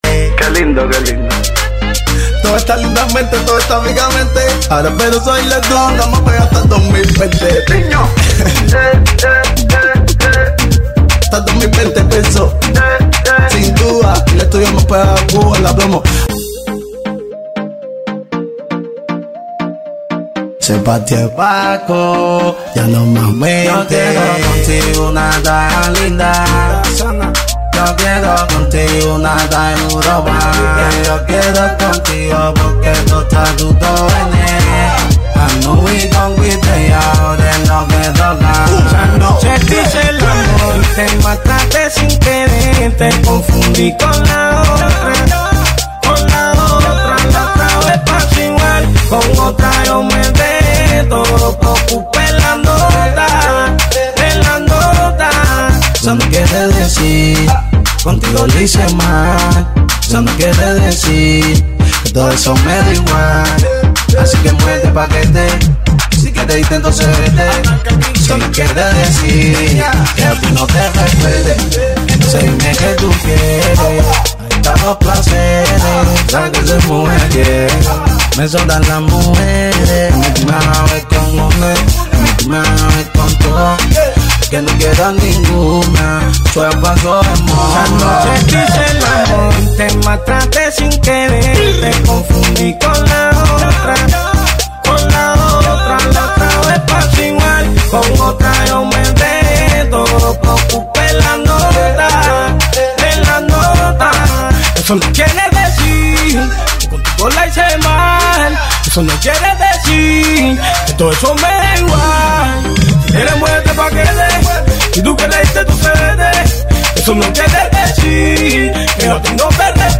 TOP OF CUBAN REPA MUSIC